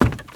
High Quality Footsteps / Wood / Wood, Creaky
STEPS Wood, Creaky, Run 22.wav